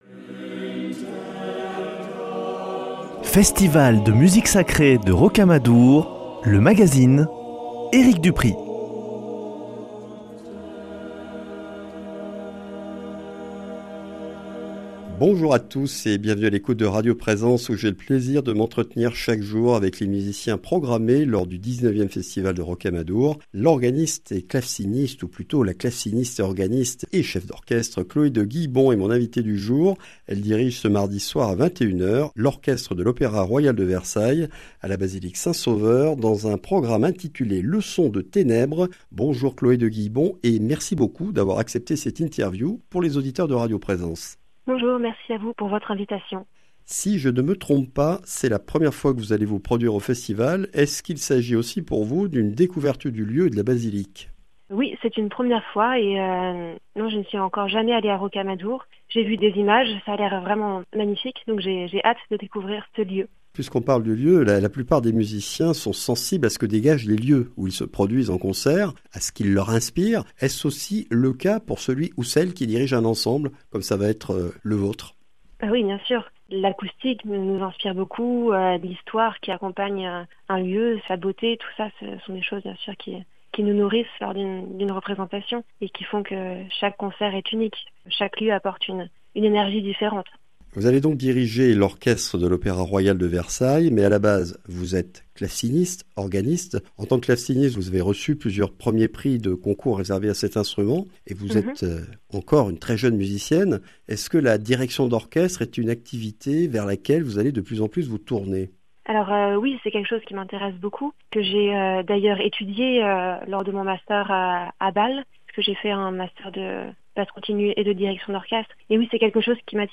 Conversation autour de son début de carrière et de son amour pour la musique baroque française.